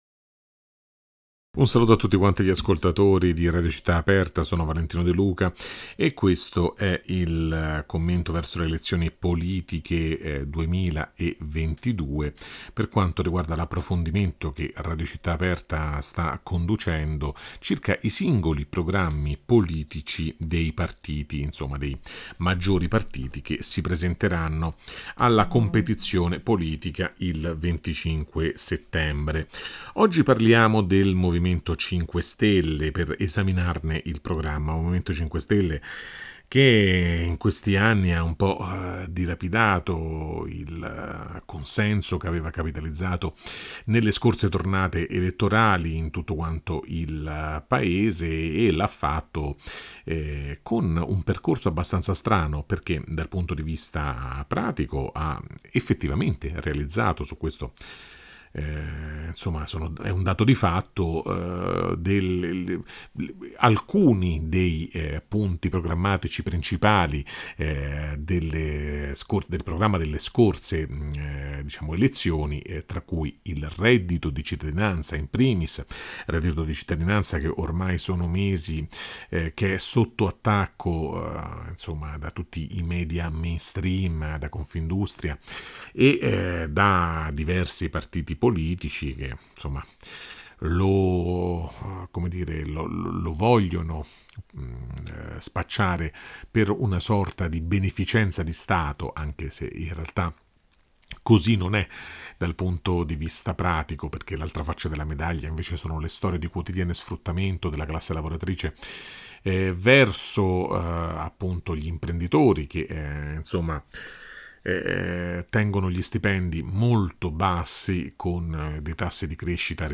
Il giornalista